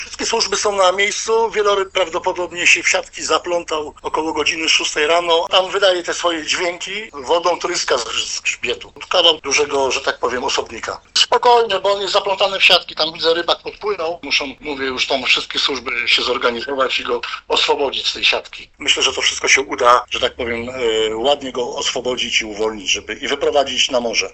O przebiegu akcji mówi miejscowy miłośnik fotografii